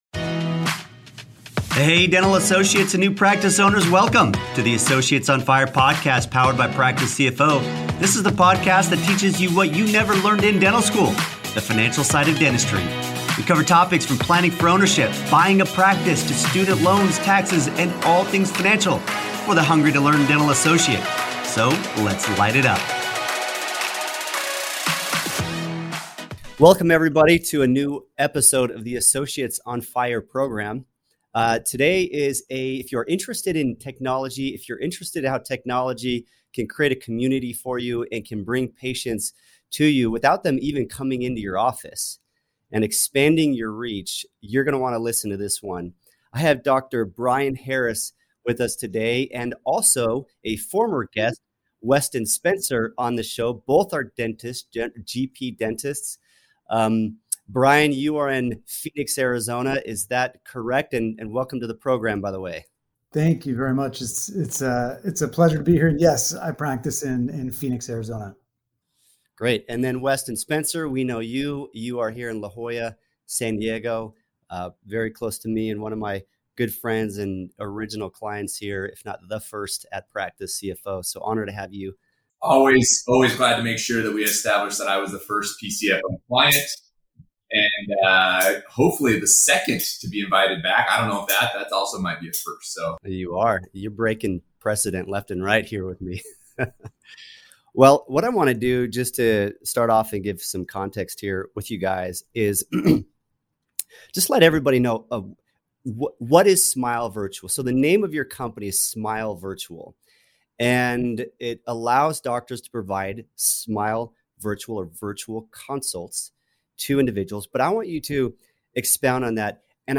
In this episode we interview